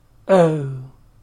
əʊ
əʊ-individual.mp3